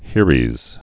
(hîrēz, -ēs)